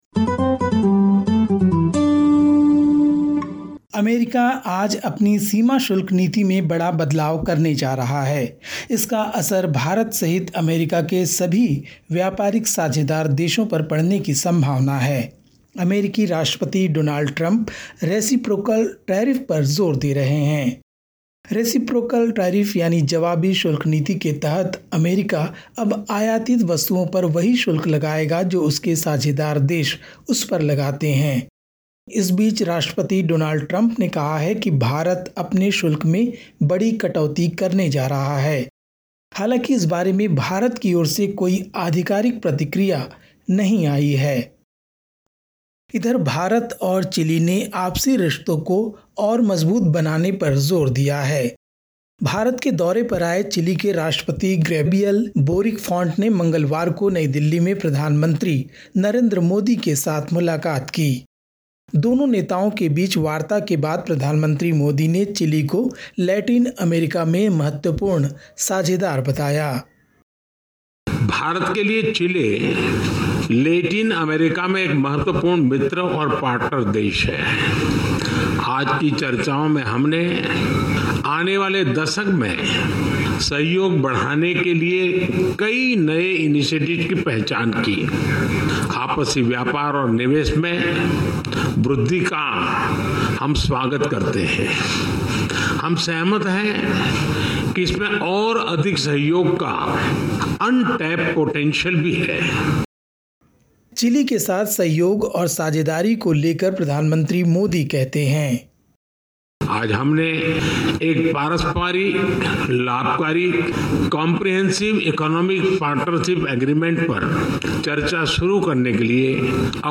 Listen to the latest SBS Hindi news from India. 02/04/2025